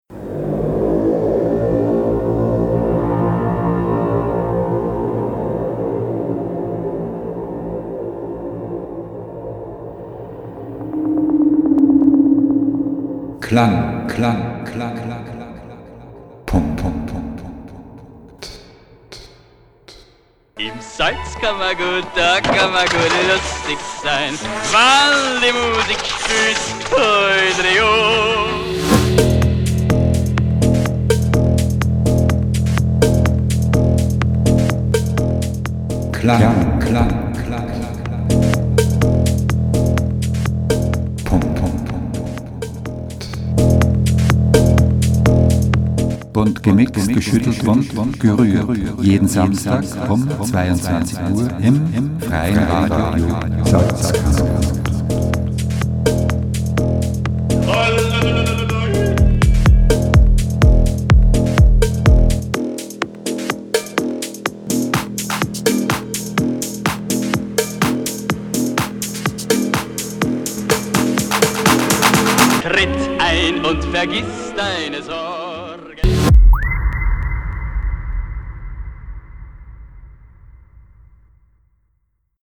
Sendungstrailer
KLANG.PUNKT. Die elektronische Wundertüte. Style: Techno, Indie Dance, Melodic House, Tech House, Deep House, Progressive House, Electronic…